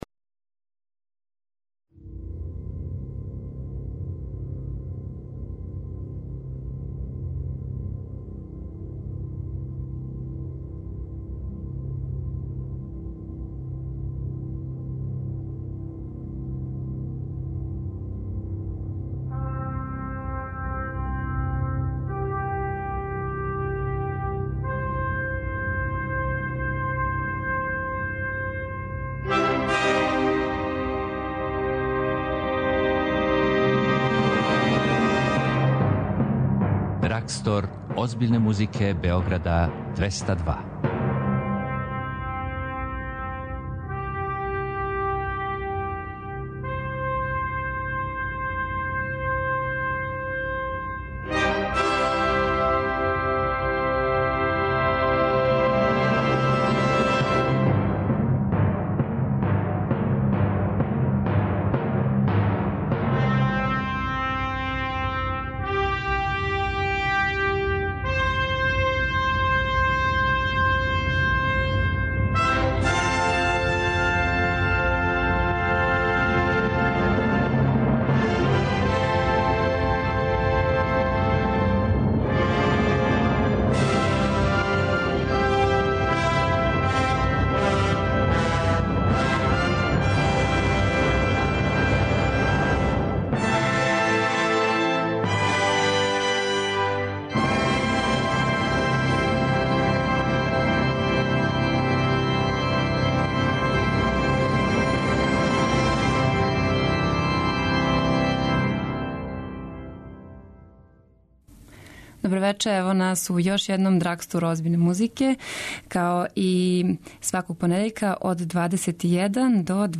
И вечерашњи Драгстор пружа разноврсну понуду уметничке музике - од Елгаровог Нимрода, Крцка Орашчића у класичној и џез верзији, филмске музике до композиција Волфганга Амадеуса Моцарта и његовог оца Леополда!